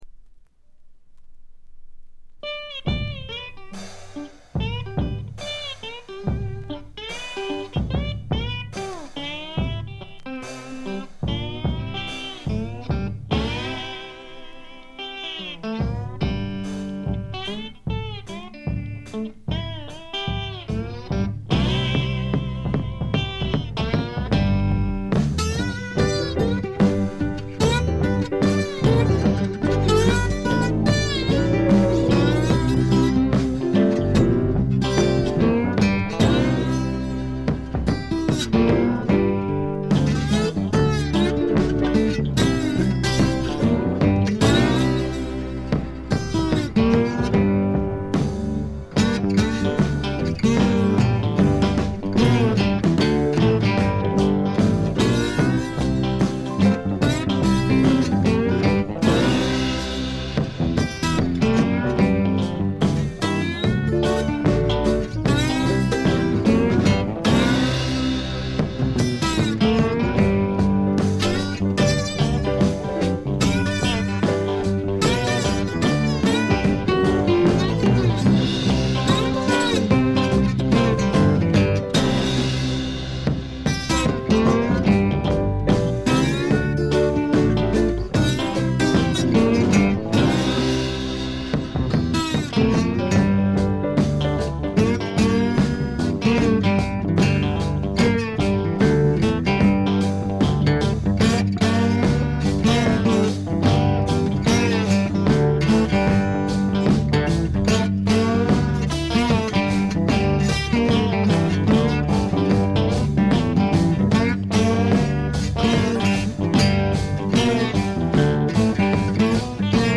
ほとんどノイズ感無し。
試聴曲は現品からの取り込み音源です。
Guitar, Mandolin, Bass